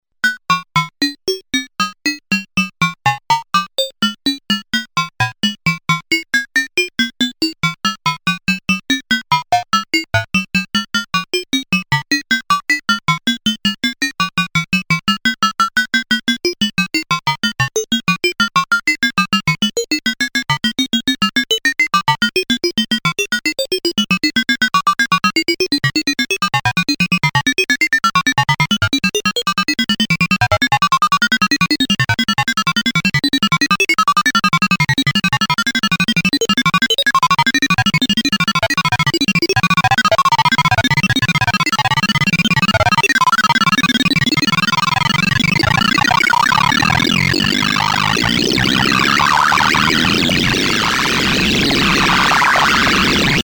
S&H with Ring Modulator
Sample & Hold from Pink noise. Ring Modulator output with VCO1 on Sine and VCO2 on square.